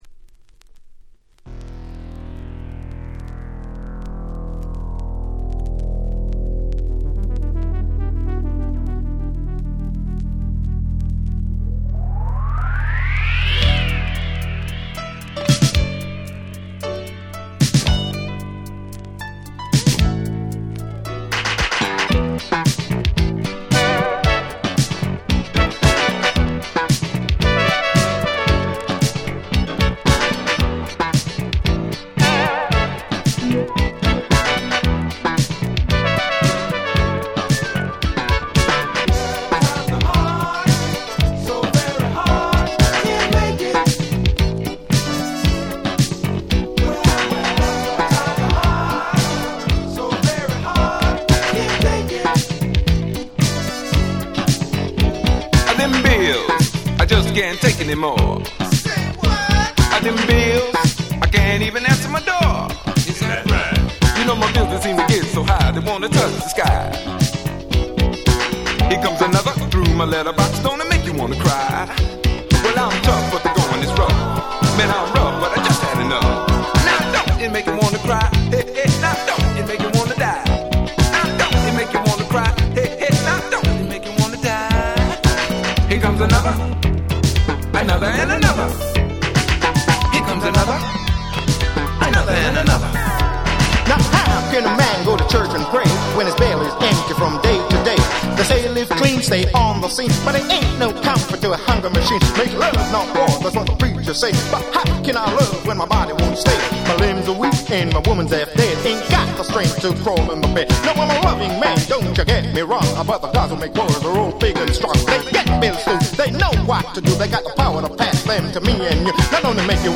83' Disco Rap / Disco超人気盤！！
キャッチーでGroovyなBoogieビートに歯切れの良いRapがめちゃ心地良い！
オールドスクール 80's